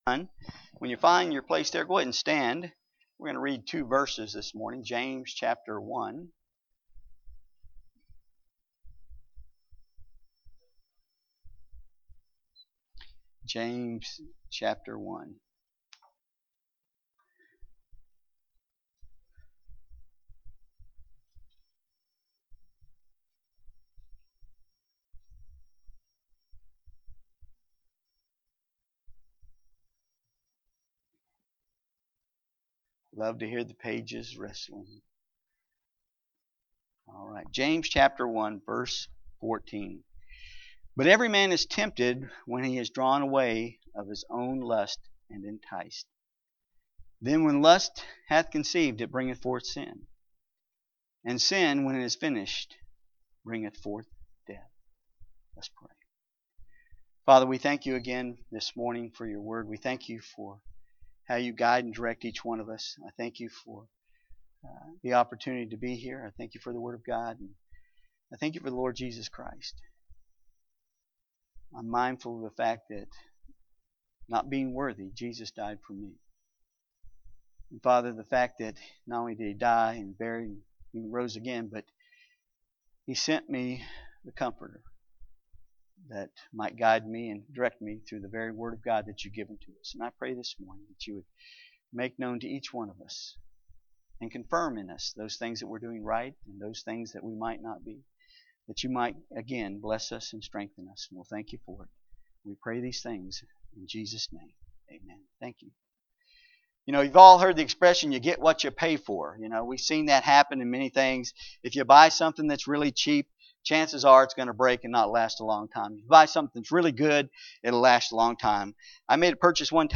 James 1:14-15 Service Type: Sunday AM « A Study in the Book of 1st Corinthians God’s Work